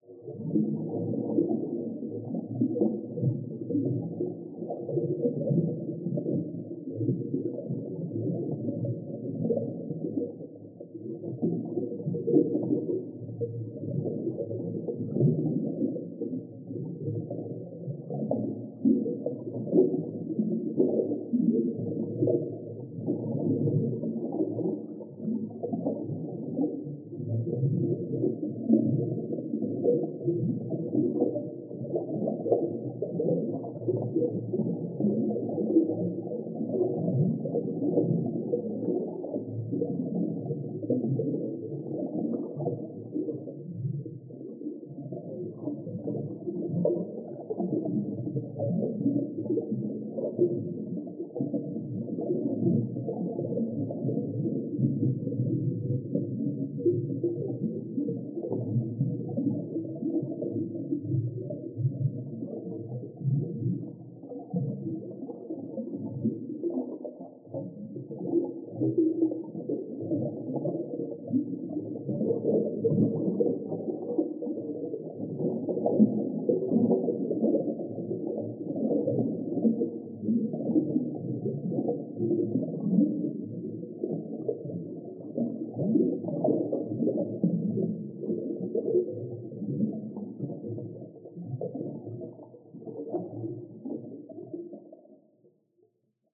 Dive Deep - Bubbles 06.wav